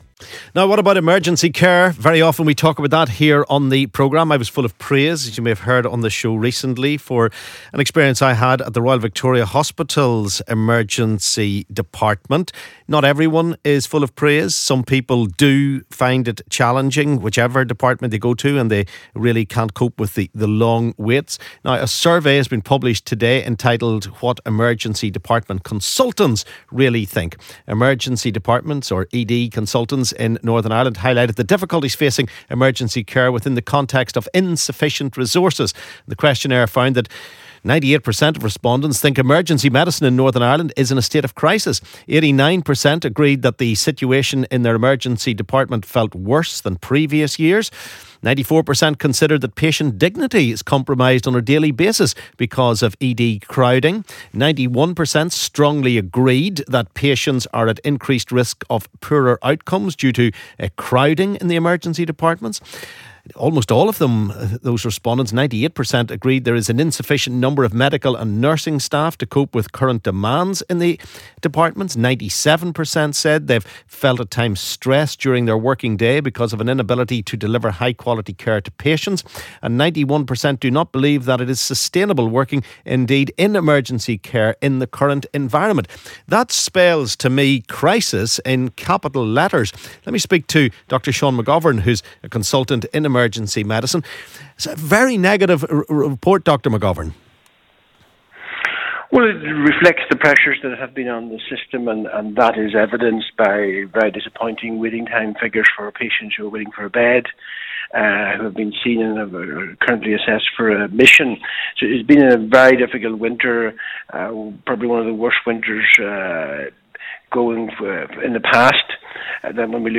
LISTEN ¦ NI's healthcare crisis deepens as Emergency Department staff speak of "appalling" state of patient care